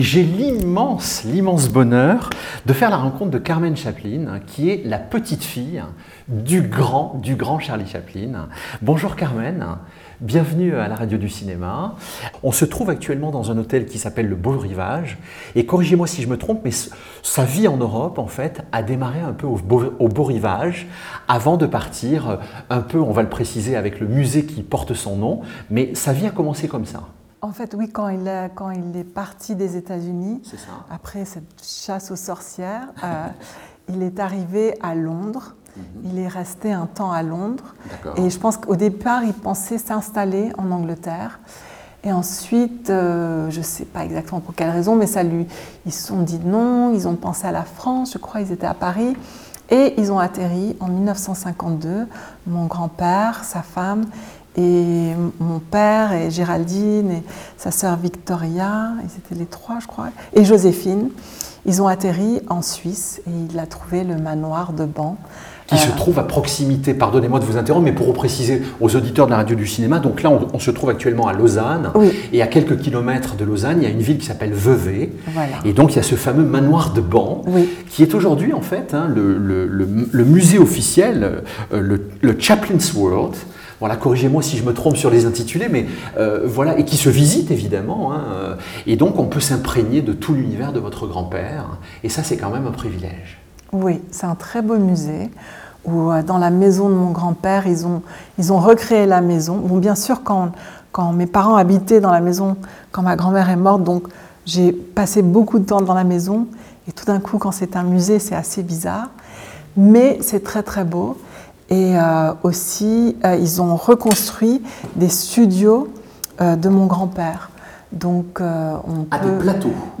La rédaction de La Radio du Cinéma • Entretien
Dans un salon du Beau-Rivage Palace, à Lausanne, Carmen Chaplin rembobine une histoire qui évoque son documentaire Chaplin | Spirit of the Tramp et le fil intime qui l’a guidée : Mon père… avait toujours été fasciné par les origines tziganes de son père, confie Carmen Chaplin au micro de La Radio du Cinéma.